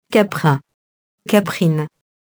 caprin, caprine [kaprɛ̃, -in]